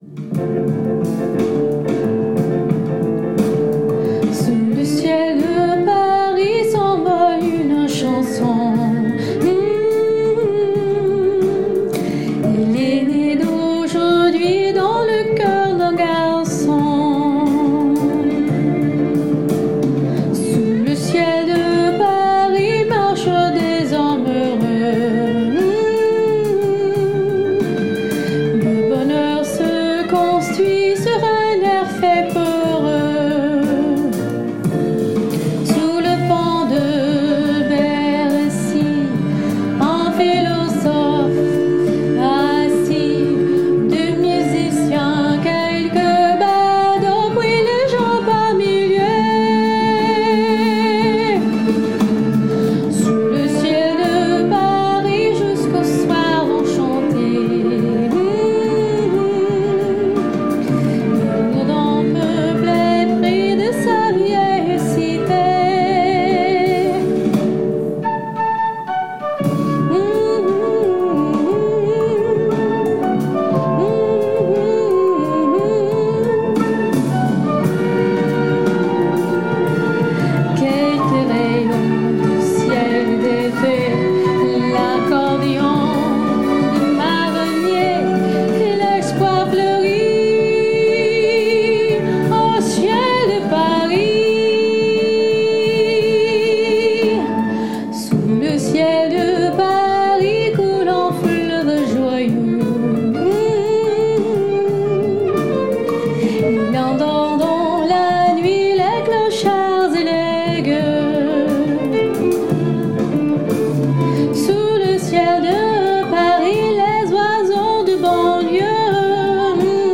Today’s song is a classic with a twist.
You have a beautiful voice !